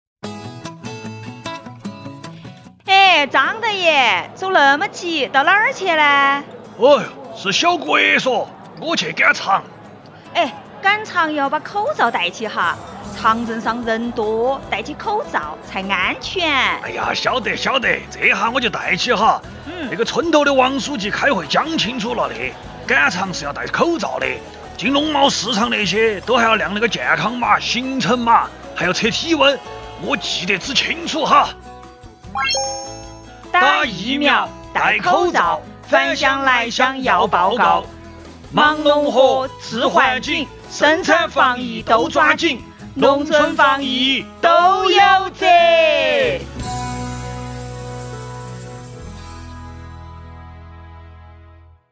川话广播剧 教你农村防疫怎么做
四川农村防疫广播剧（上）
剧中讲述的是生活、居住在四川农村的“张大爷”，与他们村社的网格员“小郭”，围绕农村疫情防控要求“碰撞”出的故事。剧中采用亲切通俗的四川话，设置了生动鲜活的两个故事情节。